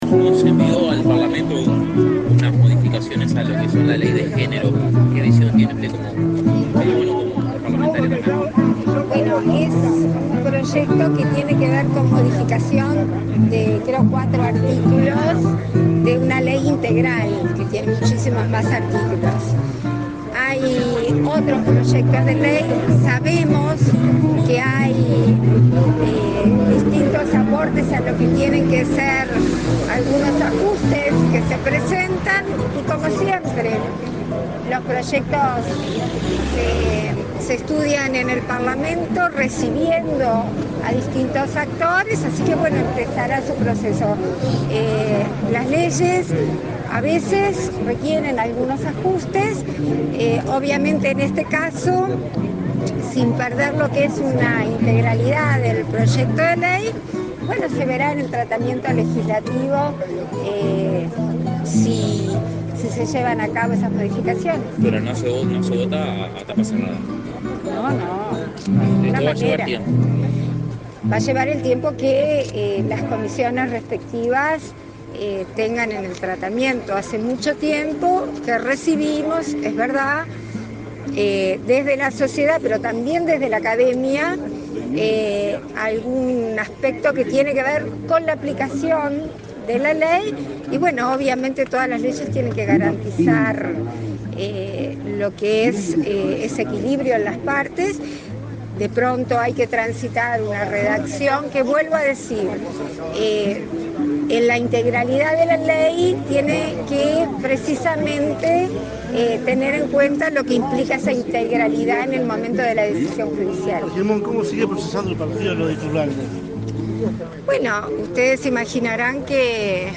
Declaraciones a la prensa de la presidenta de la República en ejercicio, Beatriz Argimón
Tras el evento, la jerarca realizó declaraciones a la prensa.